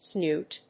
/snúːt(米国英語)/